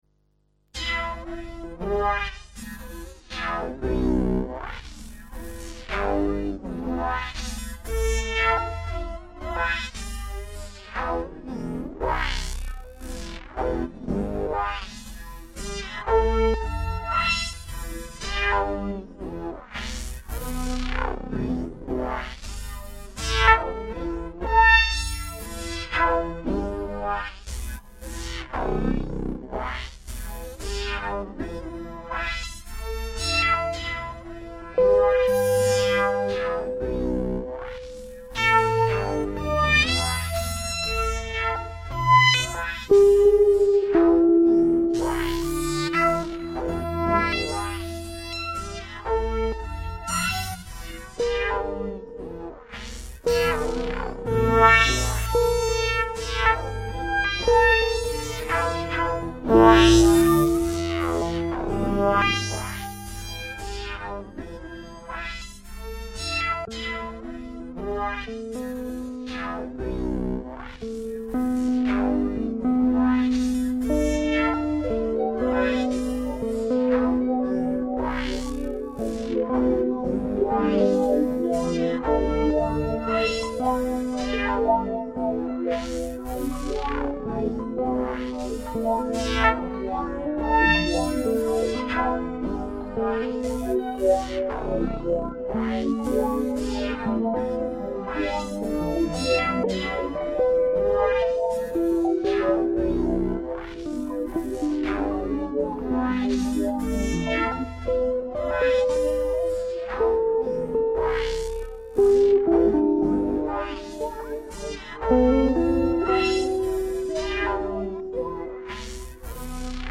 Improvisation für zwei Gitarren.
Eine Gitarre nutzt Looper und verschiedene Effekte, die 2.
Gitarre bleibt (fast) natürlich.
Gitarren